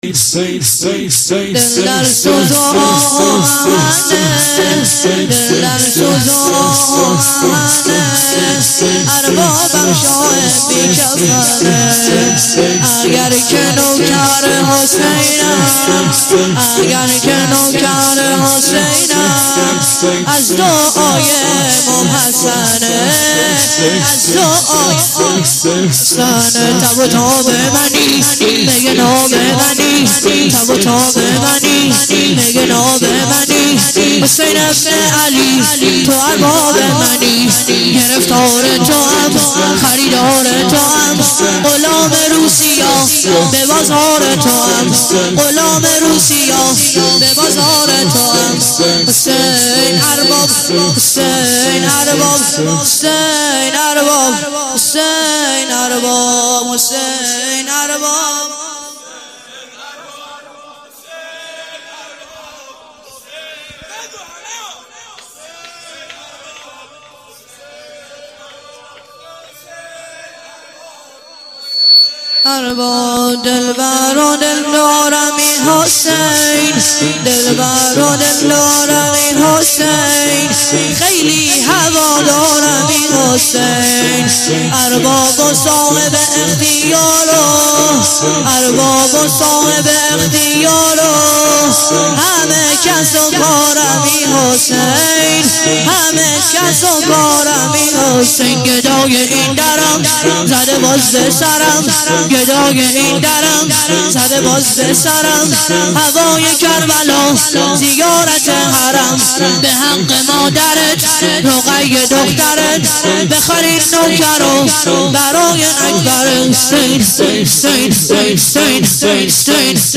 شور - دل در سوز و آه و محنه اربابم شاه بی کفنه